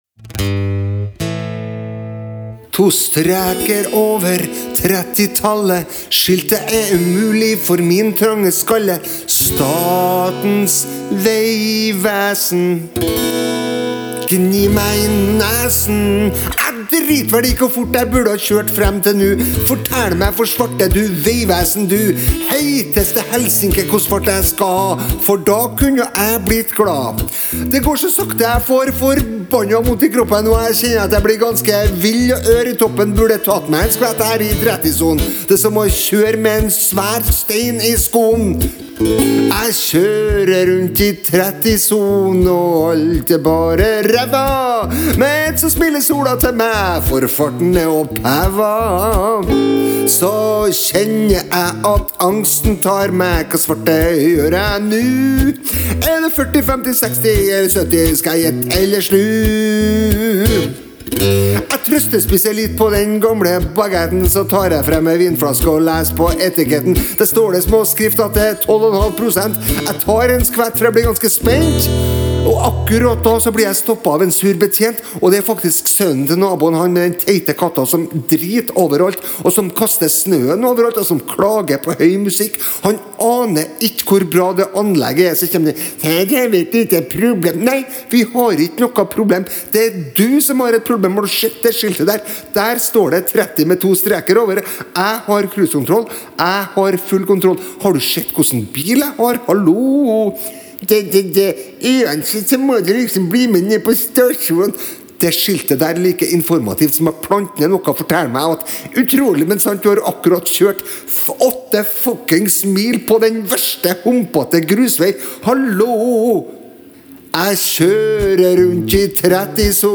Innspillingen er gjort rett inn, på andre take. Med masse improvisasjon og litt snøvling, men jeg ble fornøyd.